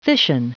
Prononciation du mot fission en anglais (fichier audio)
Prononciation du mot : fission